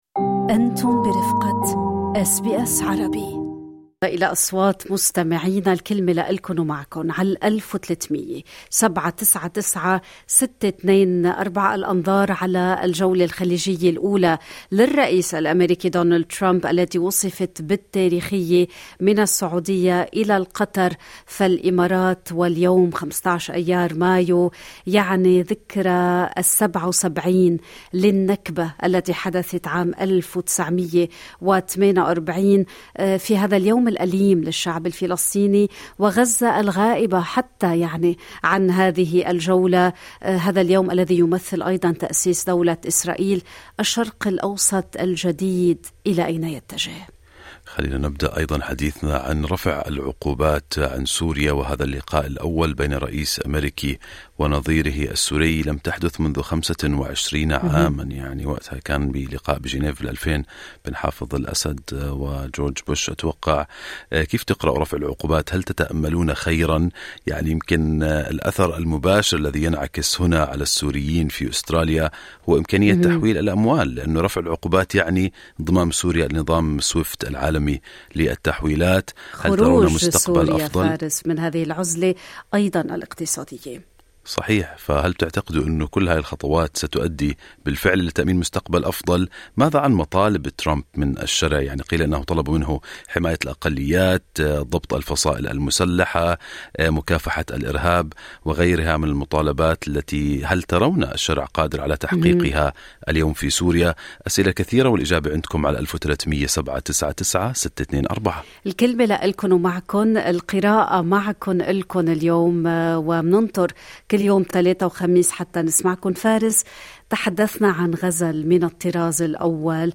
أثار إعلان الرئيس الأميركي السابق دونالد ترامب عن نيّة الولايات المتحدة رفع جميع العقوبات المفروضة على سوريا تفاعلًا واسعًا بين السوريين في مختلف أنحاء العالم. خلال "الحوار المباشر" لبرنامج صباح الخير أستراليا، عبّر عدد من المتصلين عن ترحيبهم بالقرار، معتبرين أن العقوبات كانت تُفاقم الأوضاع الاقتصادية والإنسانية في سوريا، وتضرّ بالمواطنين أكثر من النظام. في المقابل، أبدى آخرون تشككهم في دوافع القرار.